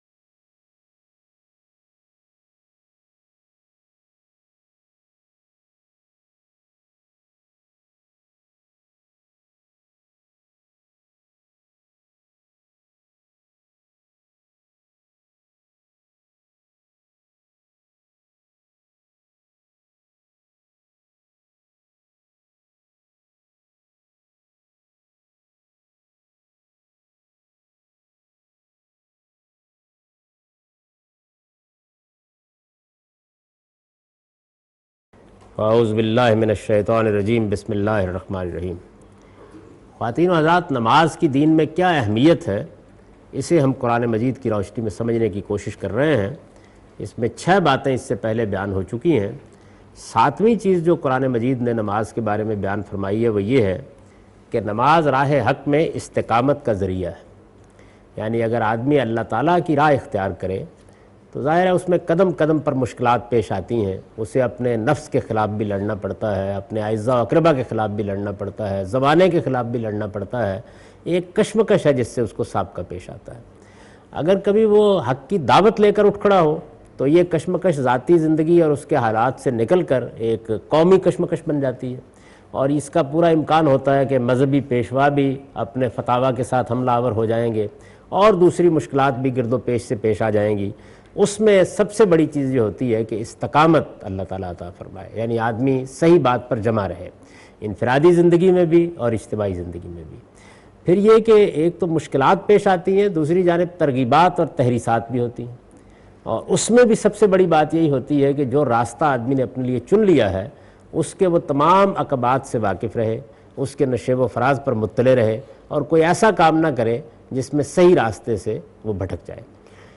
A comprehensive course on Islam, wherein Javed Ahmad Ghamidi teaches his book ‘Meezan’.
In this lecture series he teaches 'The shari'ah of worship rituals'. In this sitting he shed some light on importance of prayer (Nimaz).